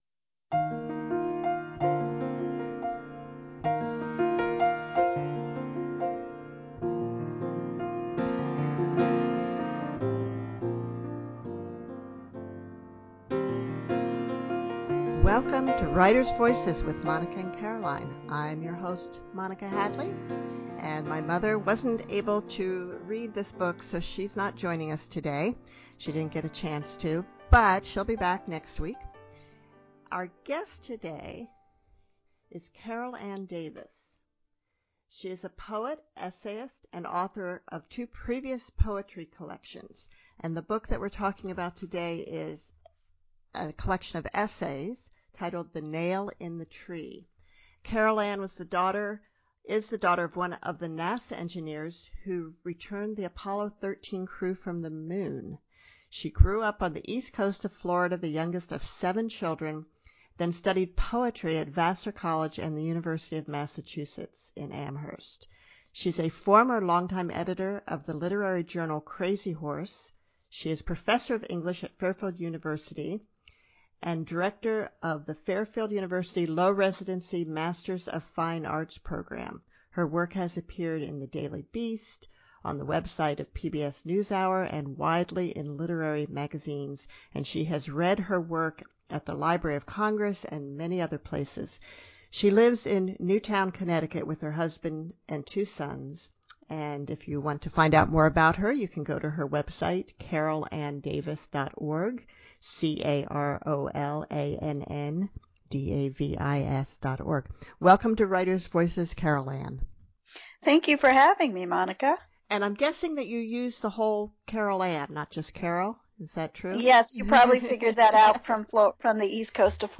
In this conversation, we talk about the healing power of writing.